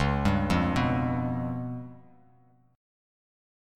C#7b5 chord